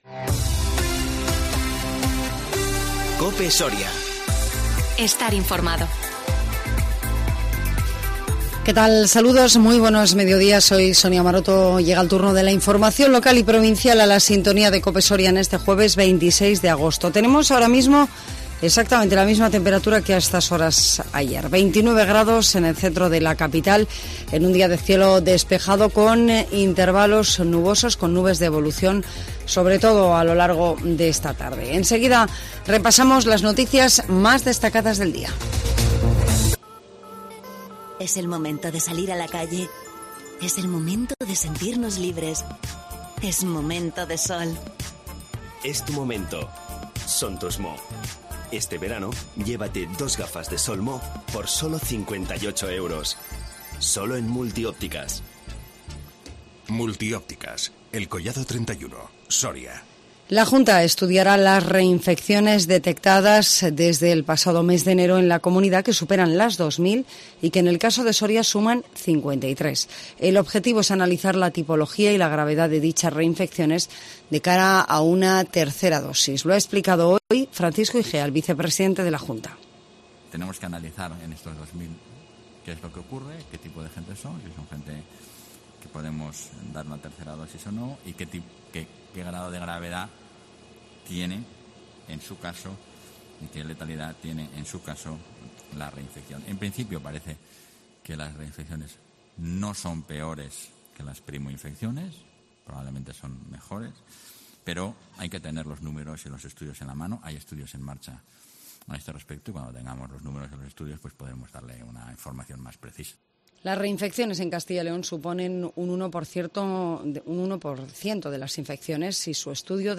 INFORMATIVO MEDIODÍA COPE SORIA 26 AGOSTO 2021